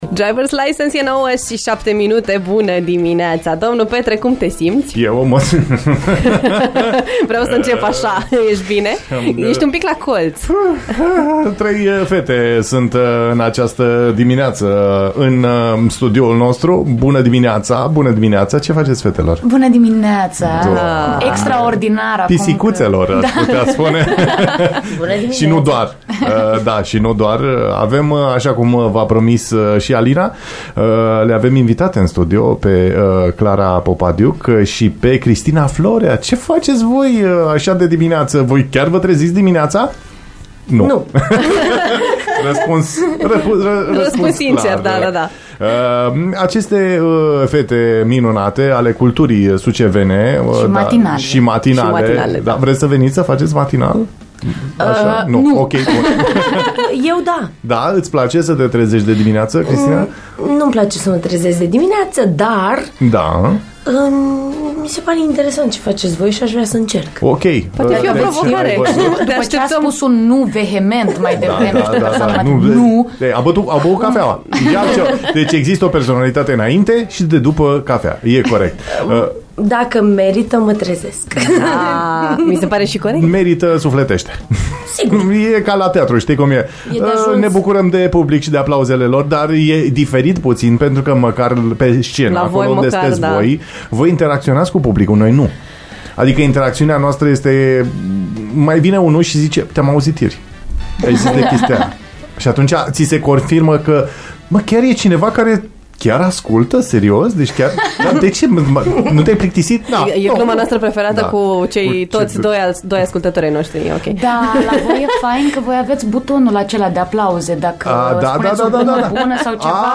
Le-am luat prin surprindere cu titulatura „actrițe de talie internațională”, dar și ele ne-au surprins cu talentul pe care îl au la microfon, ca oameni de radio.